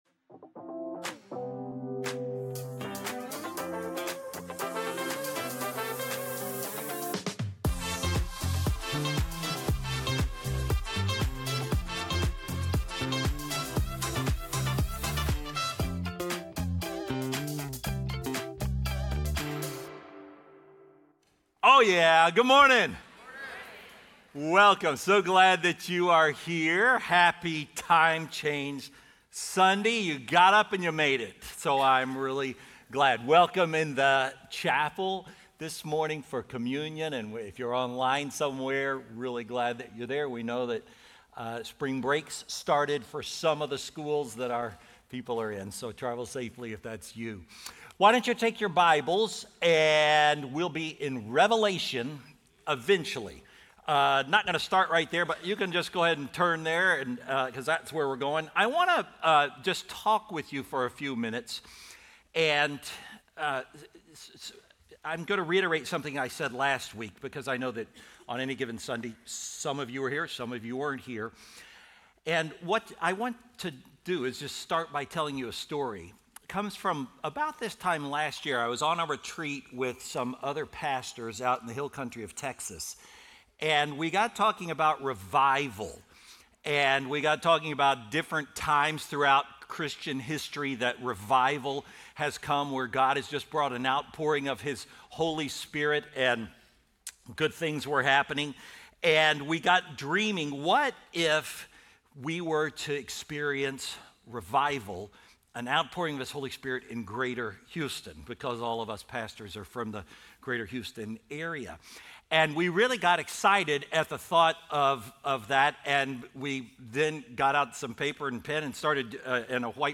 Faithbridge Sermons